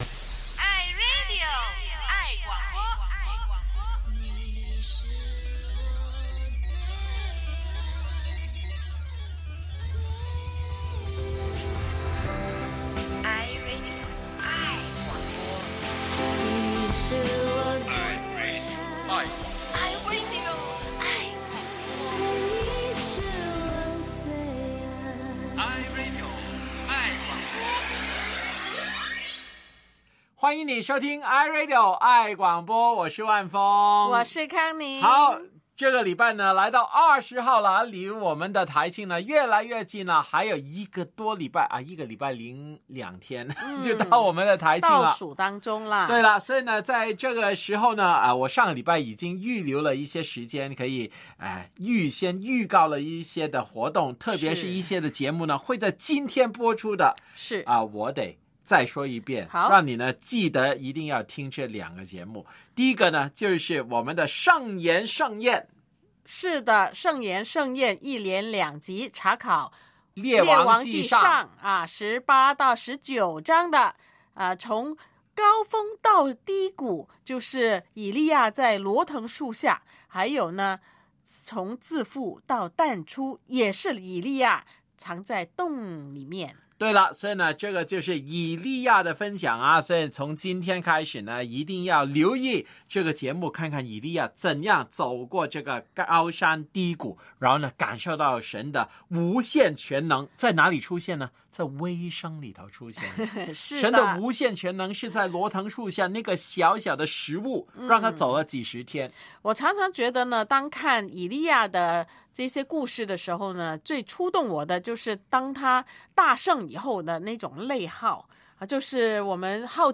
广播梦工场2.0：广播人与访问节目，主播们互相访问！